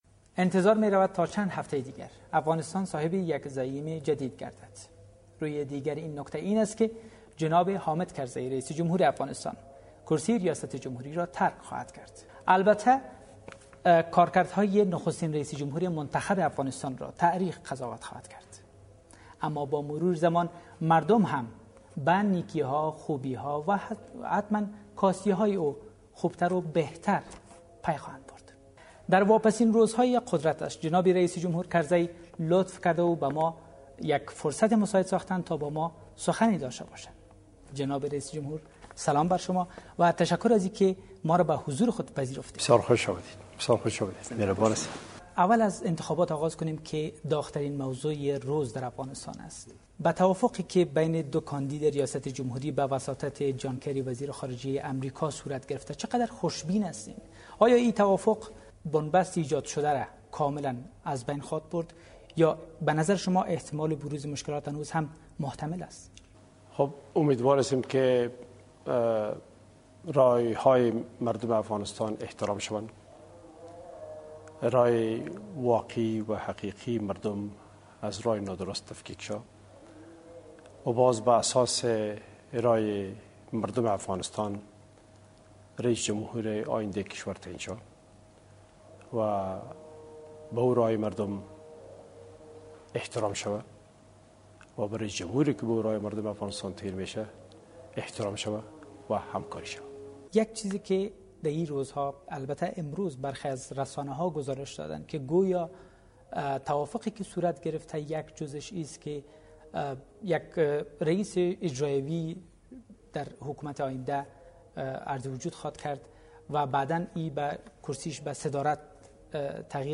karzai ashna TV full interview